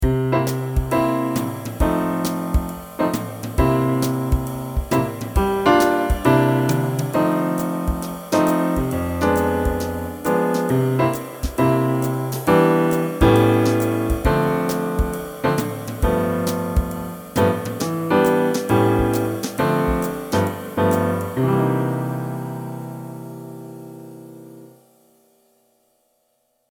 The Jazz Blues is a common reharmonization of the basic form. Notice that although the progression may sound much fancier and more complex, the core structure of tension and release is exactly the same.
4_JazzBlues.mp3